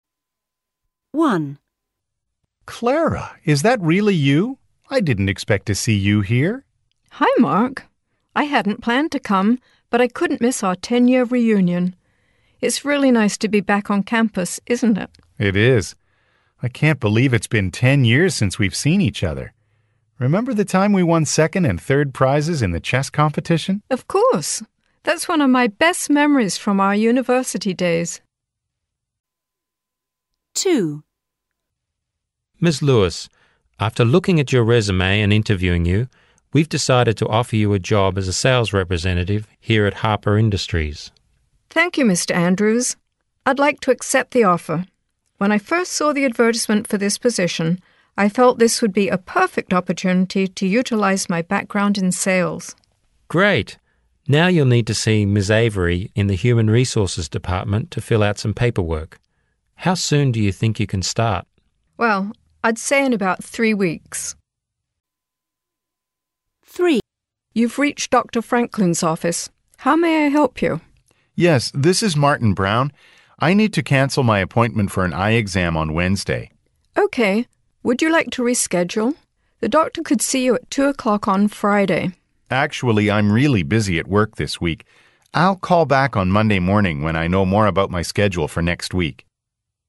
Conversation 1: